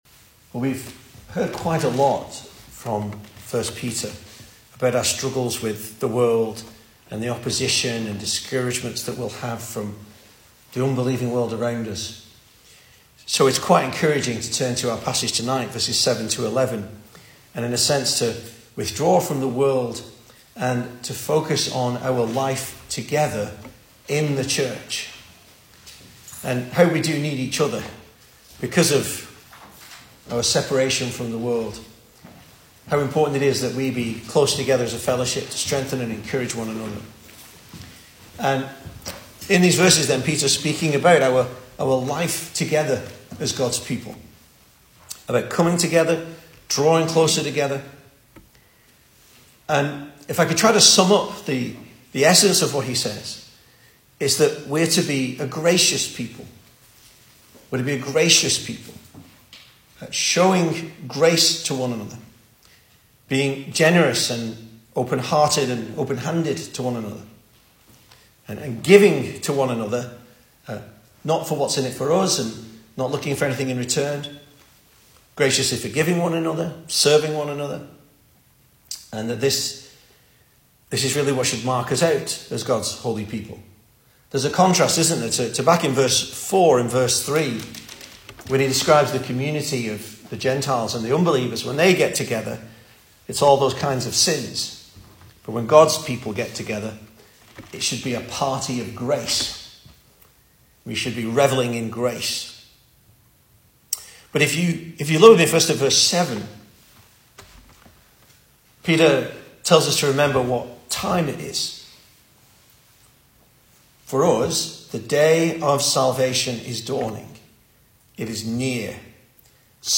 2024 Service Type: Weekday Evening Speaker